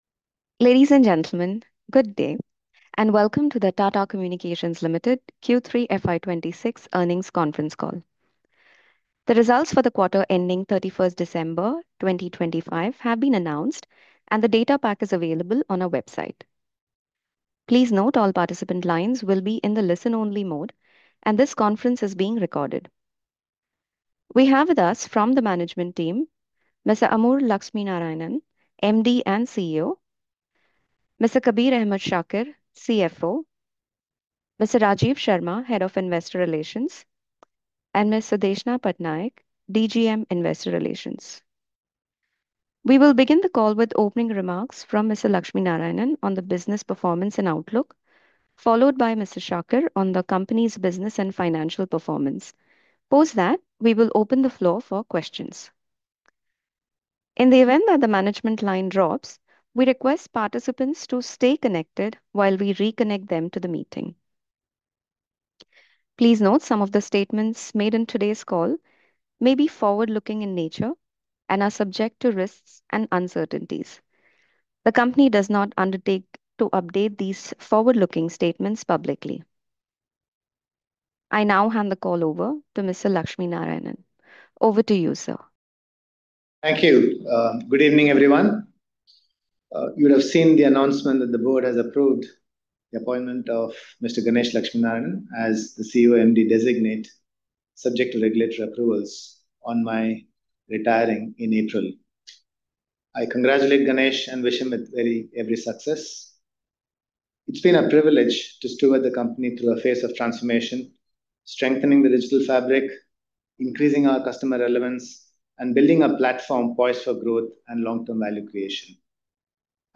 q3-fy-2026-earnings-call-recording.mp3